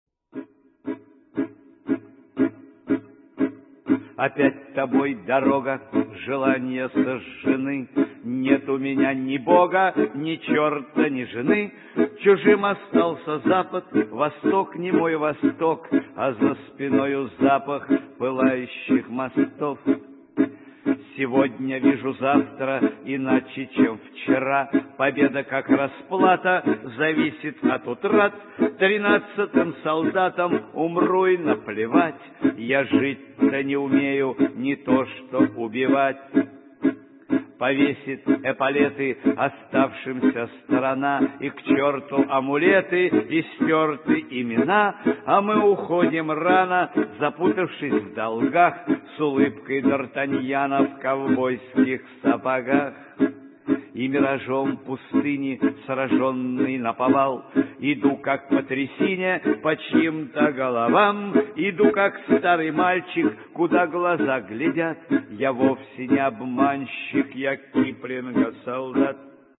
песня называется